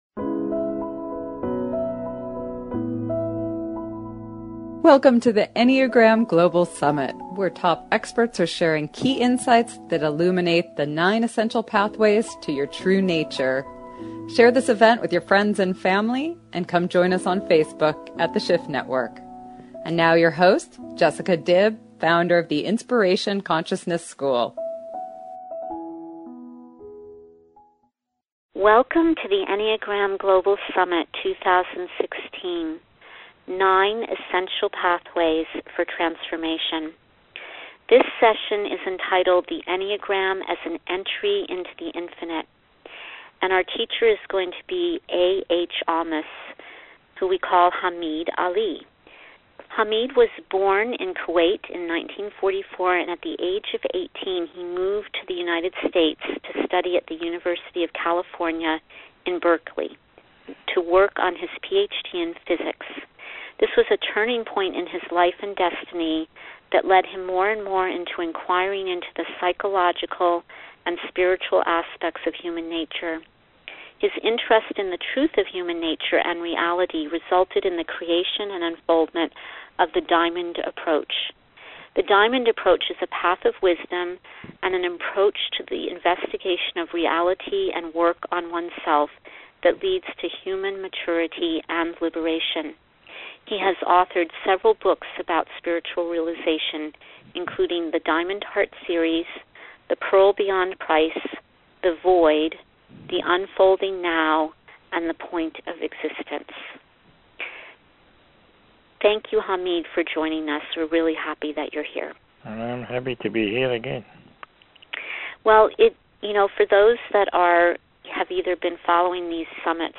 Enneagram Interview: A. H. Almaas | Ridhwan
This interview was part of the Enneagram Global Summit 2016, a free online event which featured today’s top teachers who brought together Enneagram wisdom and experience from around the globe.